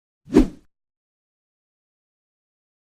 Glass Break
Glass Break is a free sfx sound effect available for download in MP3 format.
012_glass_break.mp3